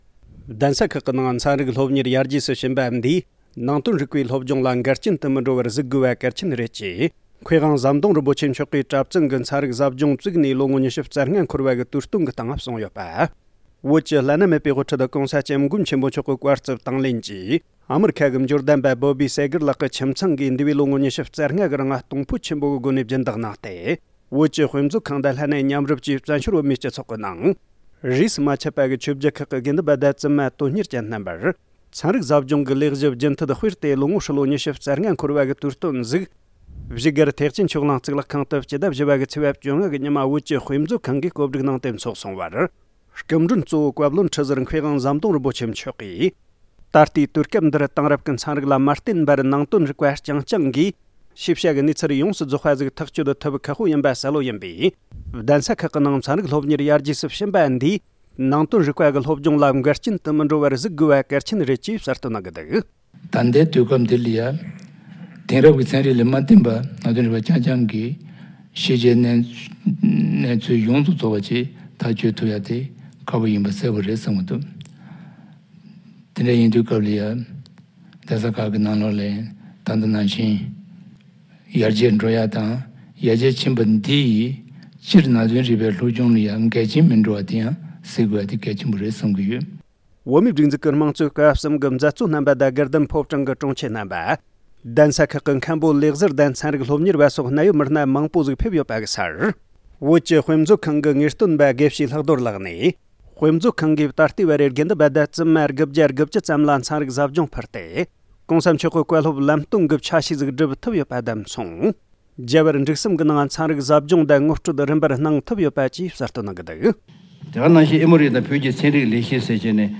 སྒྲ་ལྡན་གསར་འགྱུར། སྒྲ་ཕབ་ལེན།
བོད་ཀྱི་དཔེ་མཛོད་ཁང་གི་གྲྭ་བཙུན་གྱི་ཚན་རིག་ཟབ་སྦྱོང་བཙུགས་ནས་ལོ་༢༥འཁོར་བའི་དུས་སྟོན་སྐབས། ༢༠༢༥།༠༤།༡༥ ཉིན།